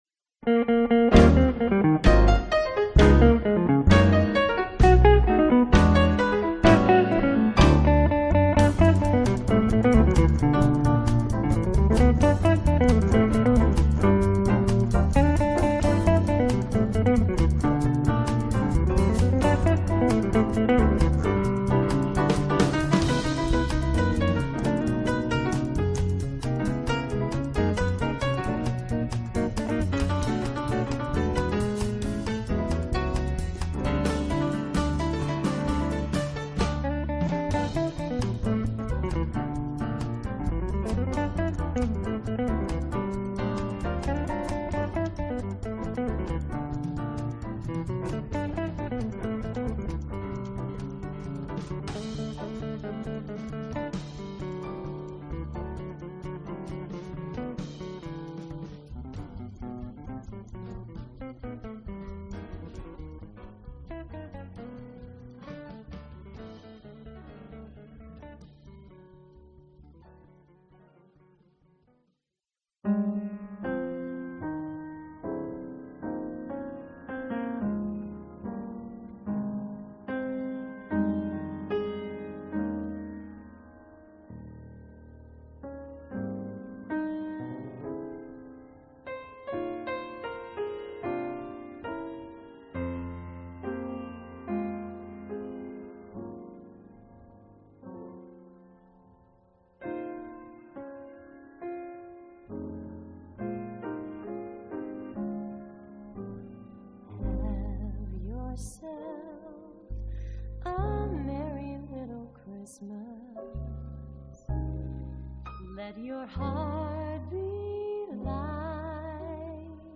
Jazz/Blues
This album swings and we think you'll love it!